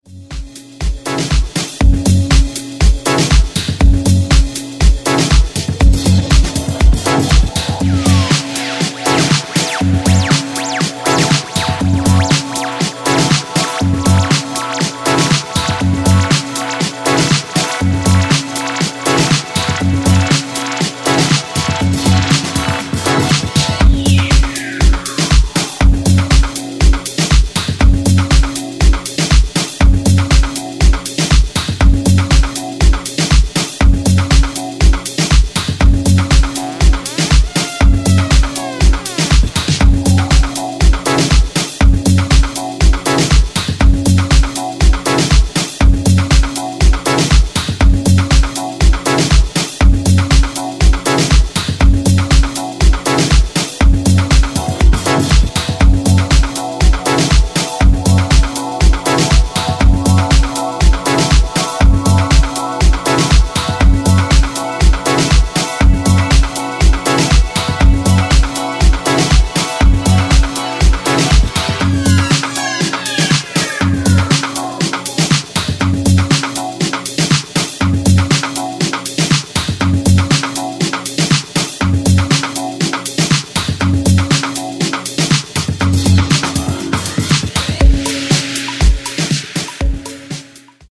outsider house label